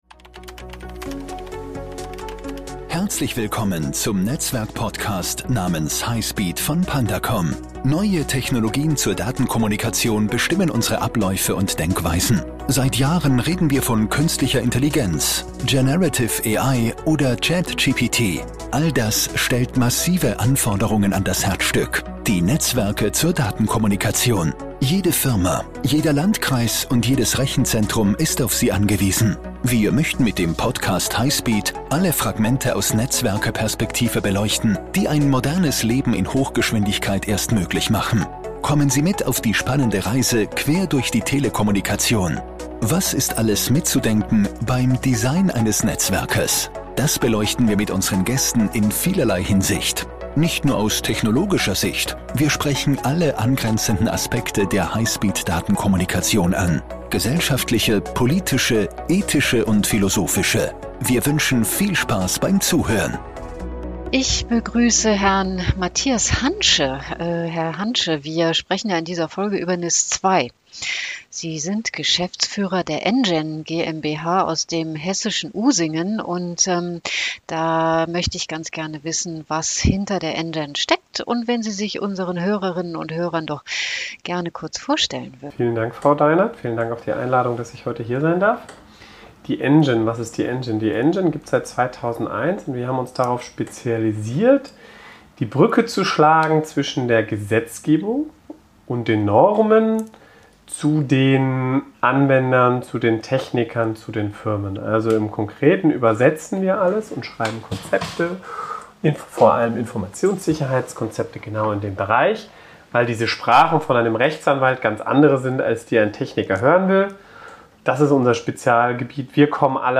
Ist die Richtlinie in der Lage, die digitale Transformation unseres Landes zu beschleunigen oder verhindert noch mehr Regulatorik noch mehr Digitalisierung? Darüber haben wir mit unserem Gast gesprochen.
Ein sehr spannendes Gespräch erwartet unsere Zuhörerinnen.